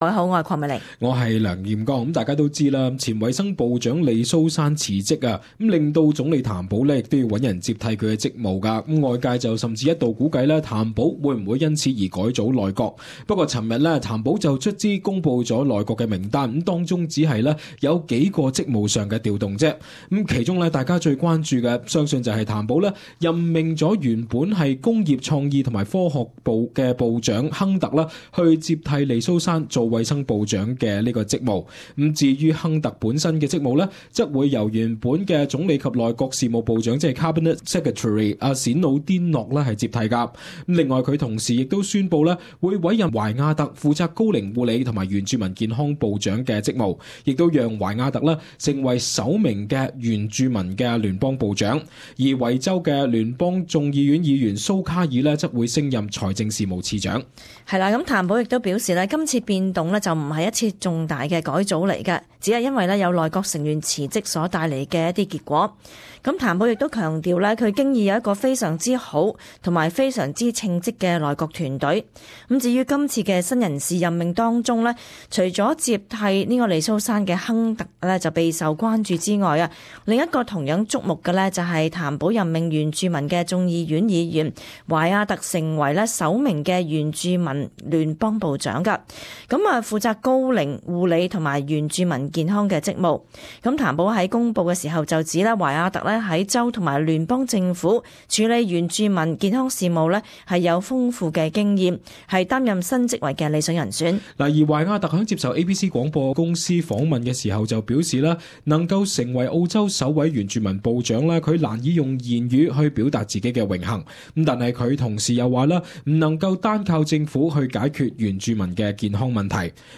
【時事報導】亨特接替利蘇珊|原住民議員懷亞特破天荒任部長